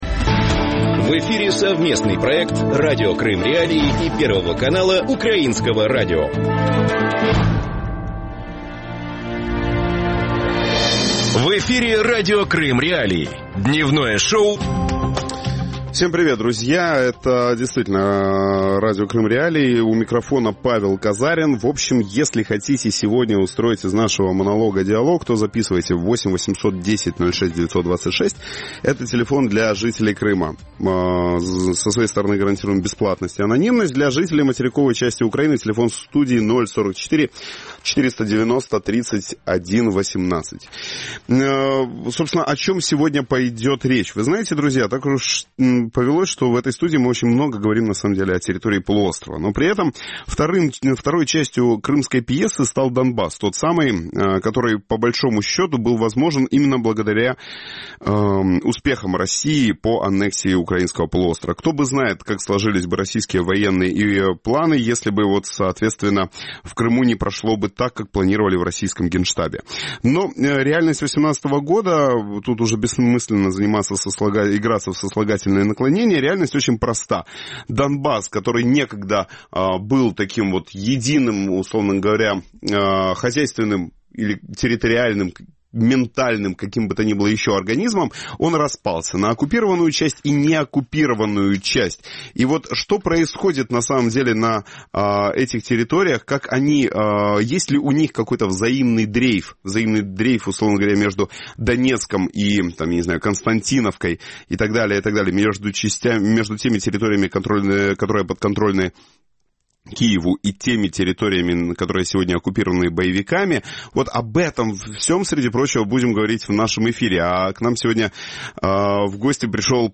Об этом в проекте «Дневное шоу» Радио с 12:10 до 12:40.